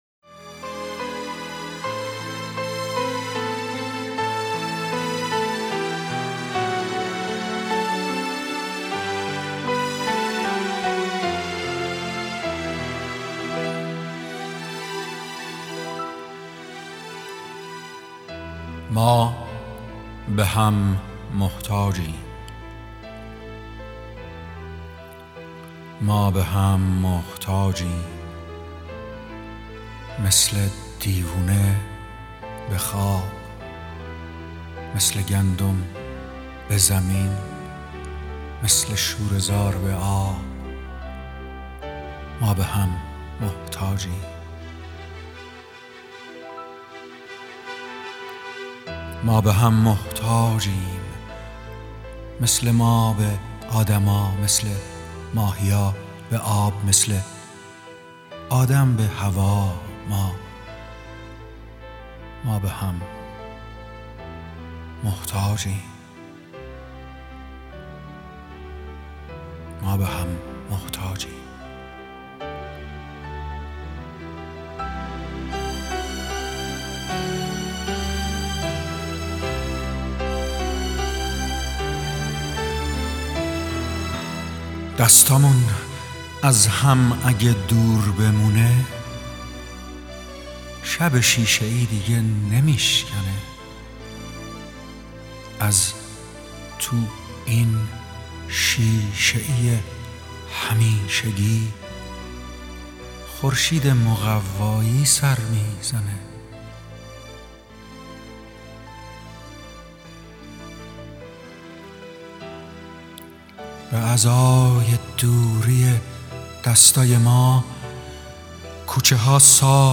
دانلود دکلمه شب شیشه ای با صدای ایرج جنتی عطایی
گوینده :   [ایرج جنتی عطایی]